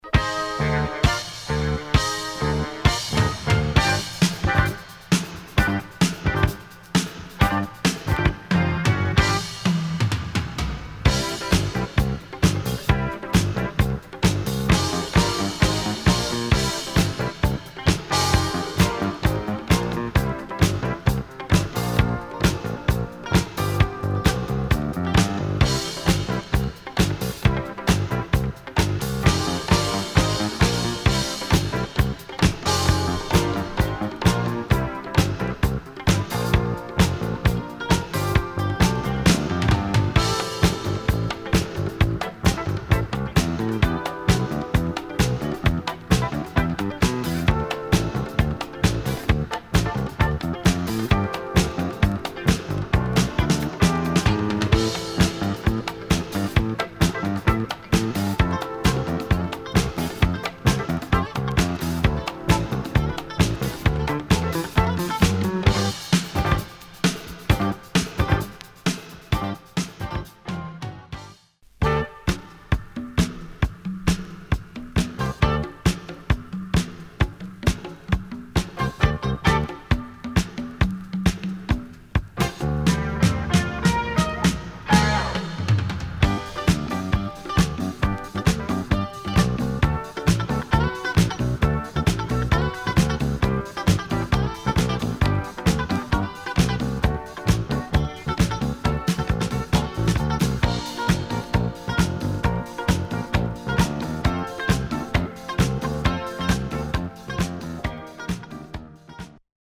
A disco funk track here
led by an electric piano.